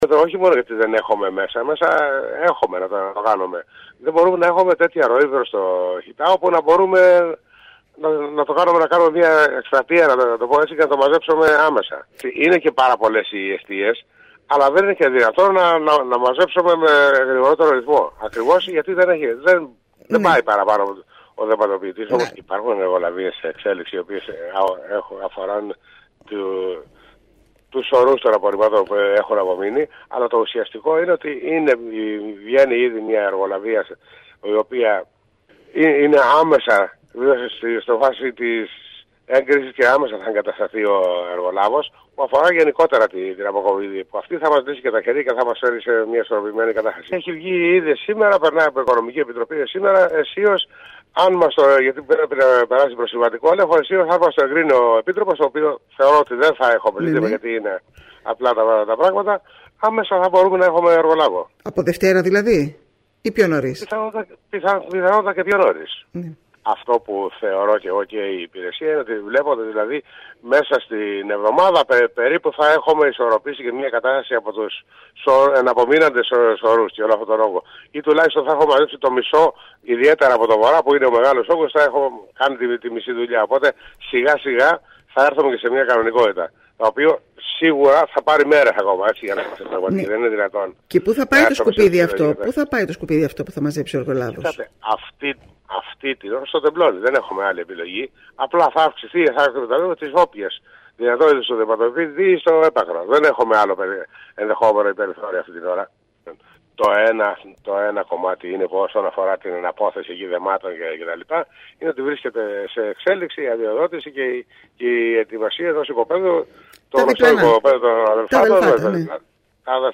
Εντός των επόμενων ημερών θα ξεκινήσει η μεγάλη εργολαβία των 220 χιλιάδων ευρώ για την αποκομιδή των απορριμμάτων, όπως δήλωσε μιλώντας στην ΕΡΤ Κέρκυρας, ο αντιδήμαρχος Καθαριότητας Σπ. Καλούδης. Ο κος Καλούδης υπογράμμισε επίσης ότι θα χρειαστούν τουλάχιστον 10 ημέρες για την απομάκρυνσης των συσσωρευμένων  σκουπιδιών που αναγκαστικά θα πρέπει να εναποτεθούν στο Τεμπλόνι καθώς δεν υπάρχει άλλος διαθέσιμος χώρος.